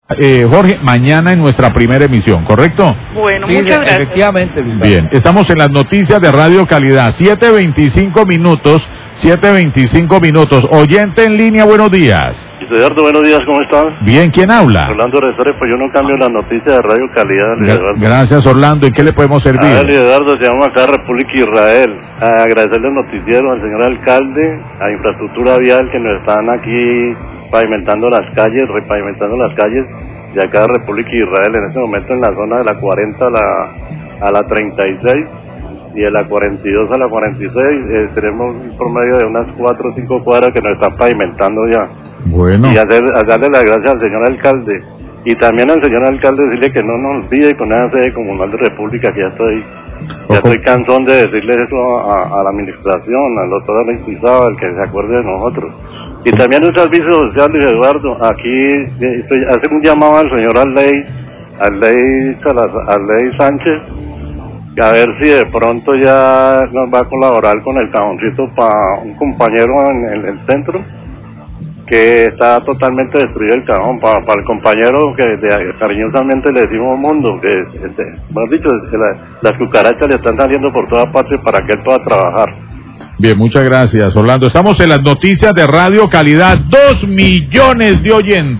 Radio
Habitante del barrio de Republica de Israel agradece al noticiero, al alcalde y a la secretaría de infraestructura porque ya se encuentra realizando las labores de repavimentación de las calles, en la zona de la 40 a la 36 y de la 42 a la 46. Y hace el llamado al Alcalde para que no olvide la sede comunal de Republica de Israel.